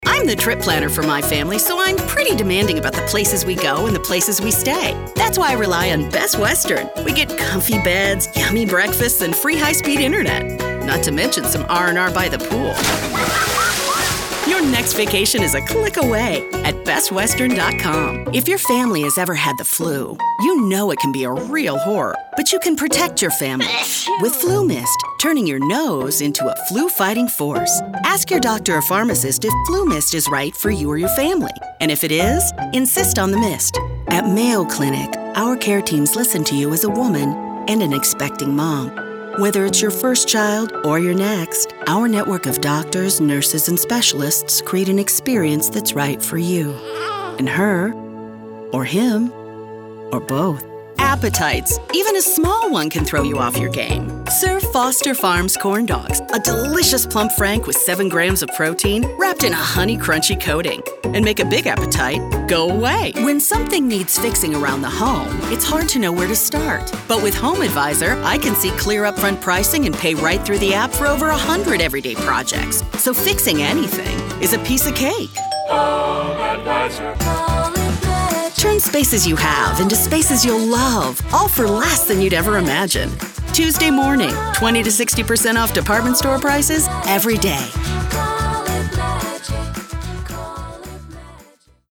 Corporate Narration Demo
English (North American)
Middle Aged